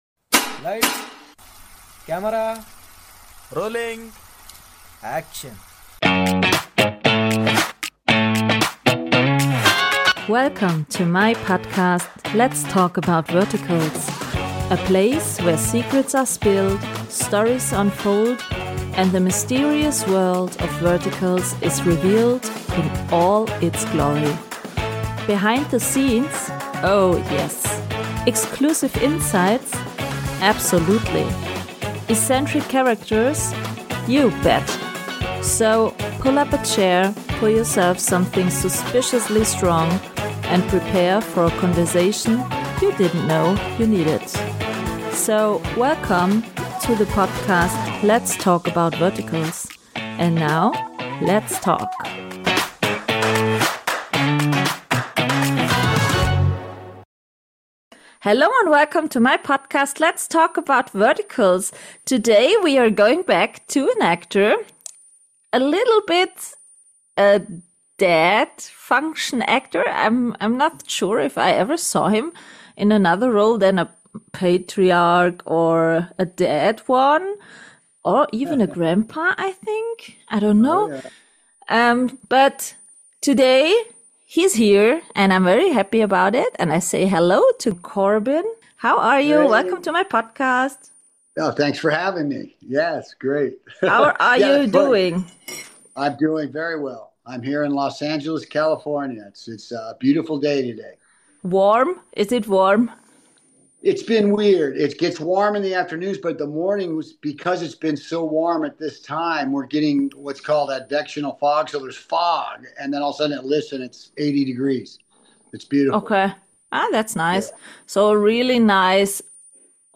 Today’s conversation